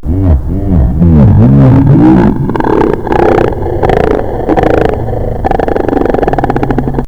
Eerie1.wav This is a freaky laugh!
eerie1.wav